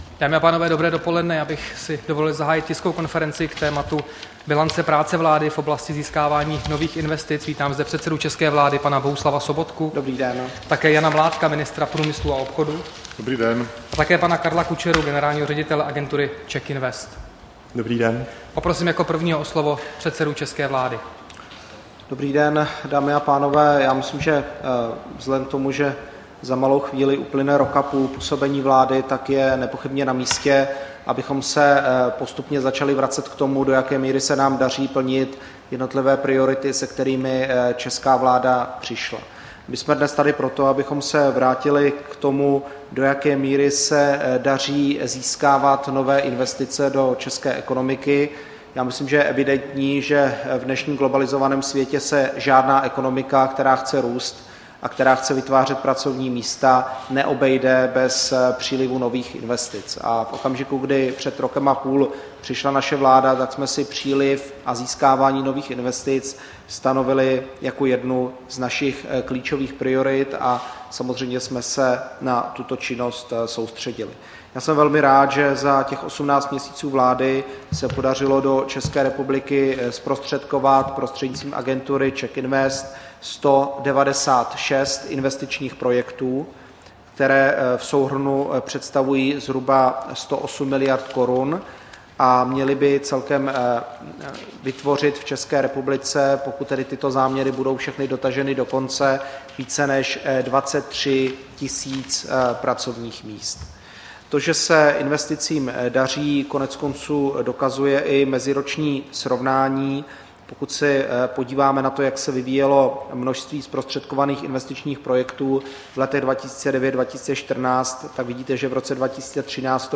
Tisková konference k zhodnocení uplynulých 18 měsíců vlády z pohledu investic, 16. července 2015